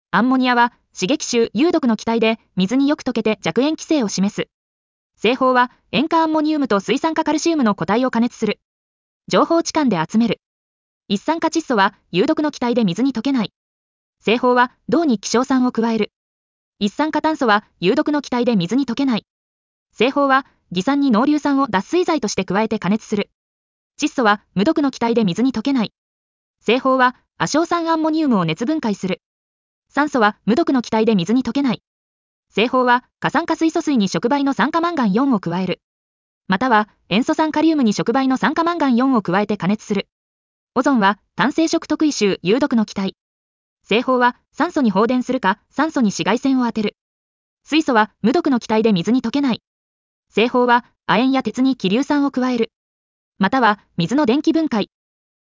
• 耳たこ音読では音声ファイルを再生して要点を音読します。通学時間などのスキマ学習に最適です。
ナレーション 音読さん